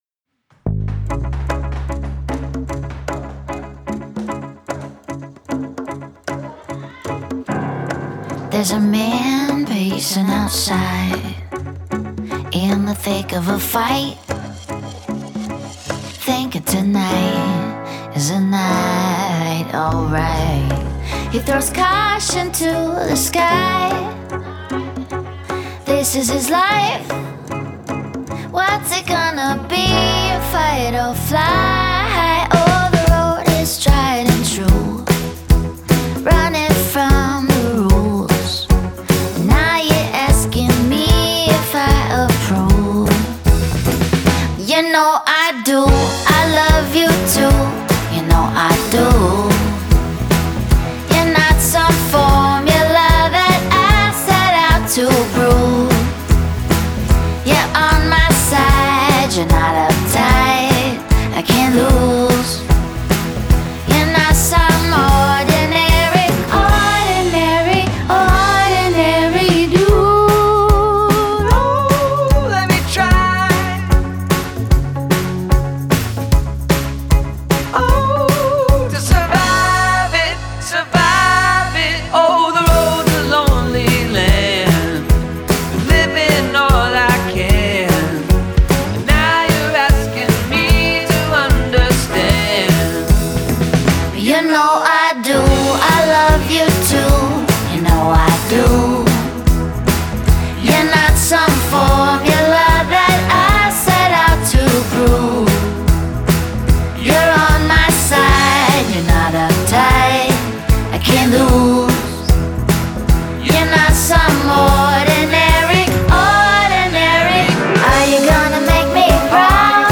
Genre: Singer/Songwriter, Indie Pop, Rock, Folk